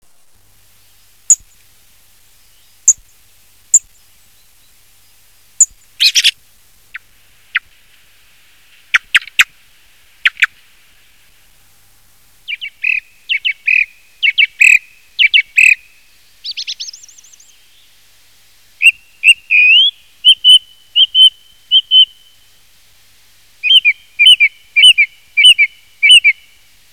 Il tordo, il prorompente canto primaverile
tordo c.wav